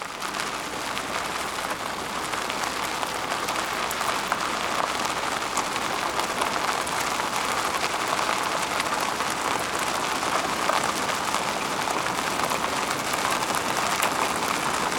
DeltaVR/Vehicles, Tire, Car, Hyundai Trajet 2006, Drive, Dirt Road Track, External, Wheels, Stones 02 SND39594.wav at 187c700f9c9718e8b1c2ec03772d35e772aebf9e